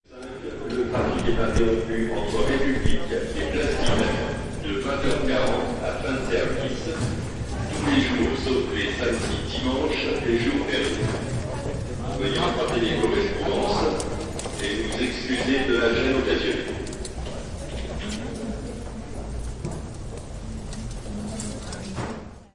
Funk Tele Am110 bpm
描述：在Telecaster上以110 bpm的速度演奏的时髦的节奏部分。
标签： 回路 电视广播员 芬克 吉他
声道立体声